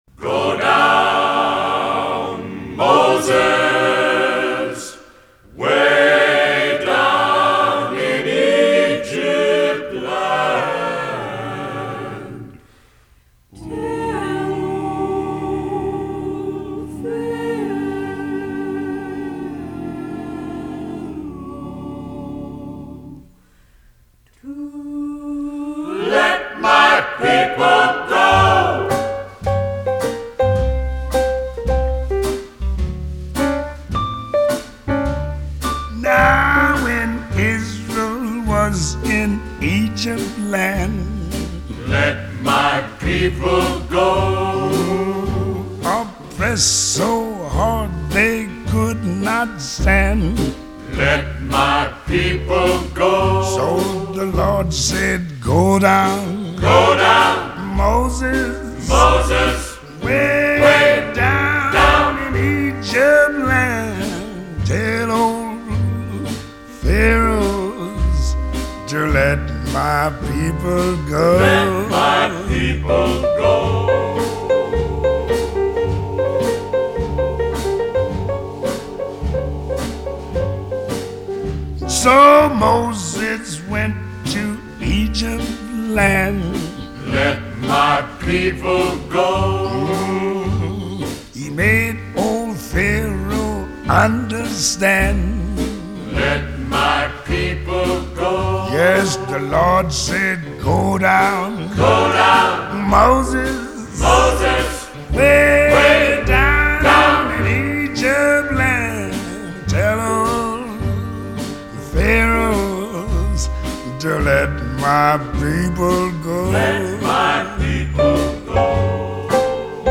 Классический госпел, конечно для православной церкви необычно, но вполне подходит для исполнения хором семинаристов.
louis-armstrong---lets-my-people-go-(8.4mb).mp3